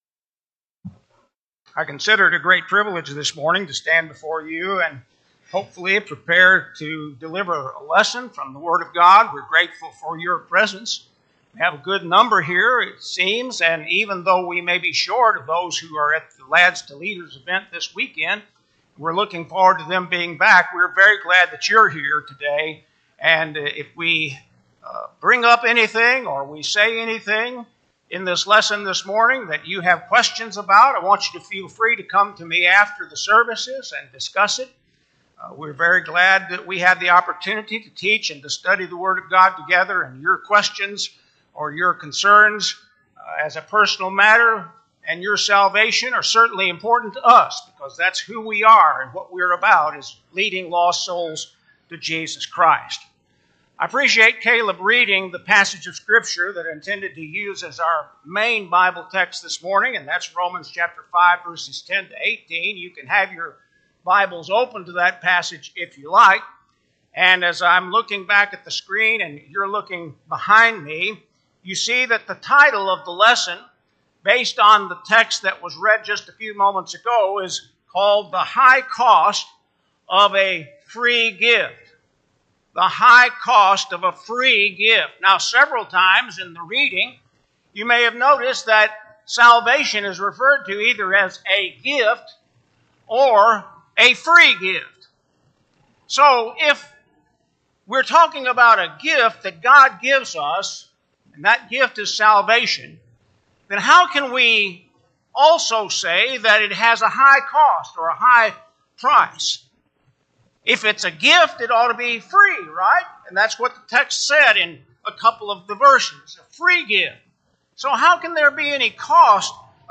4-20-25-Sunday-AM-Sermon.mp3